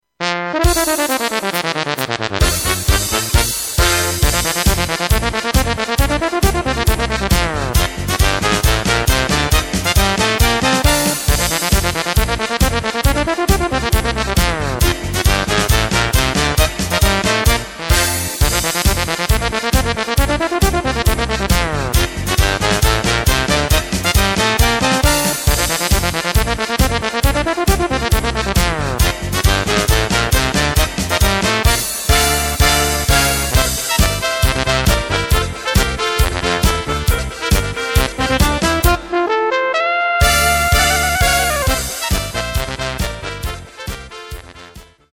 Takt:          2/4
Tempo:         136.00
Tonart:            Bb
Polka für Bariton Solo!